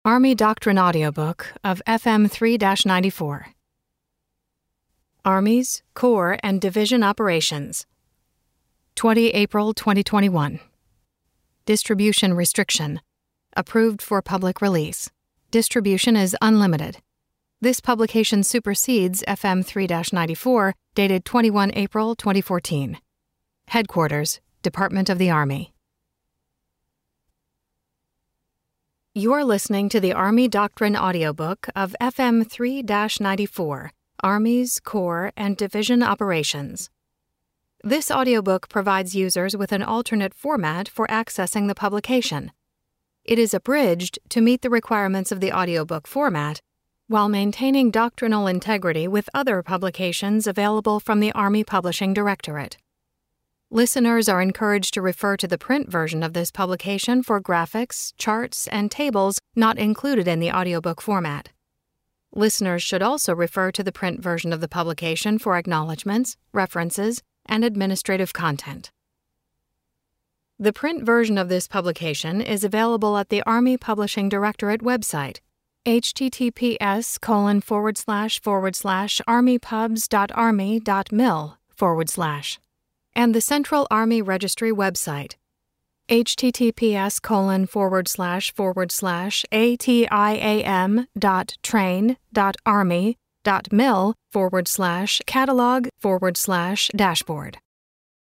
This is the download page for the Army Doctrine Audiobook of FM 3-94, Armies, Corps, and Division Operations (July 2021).